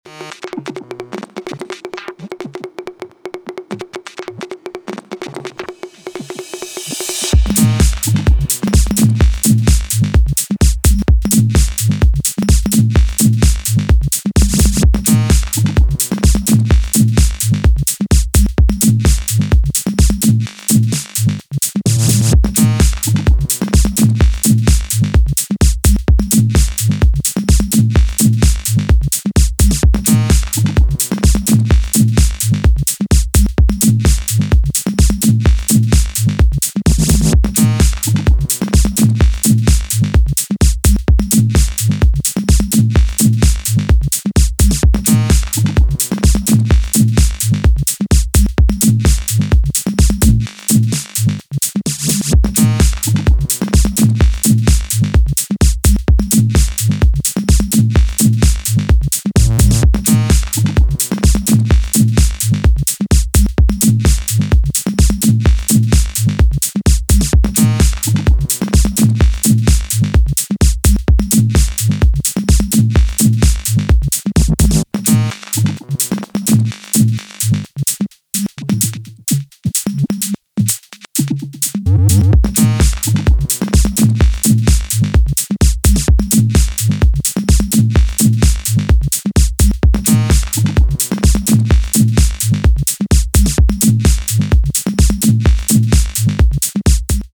Genres Minimal